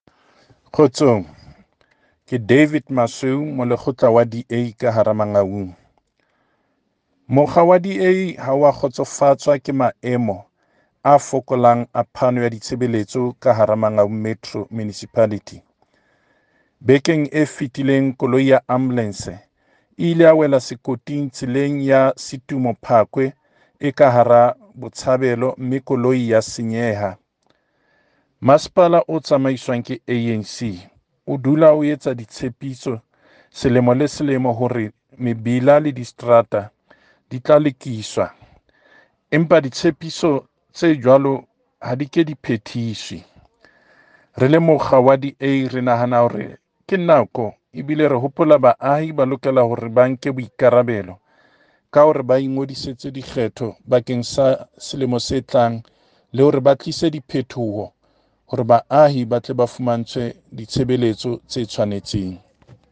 Sotho soundbites by Cllr Moshe Lefuma.